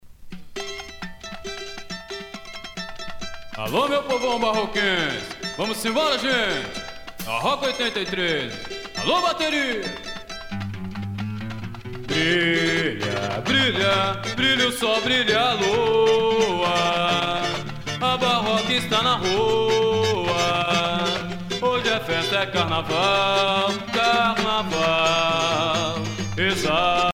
danse : samba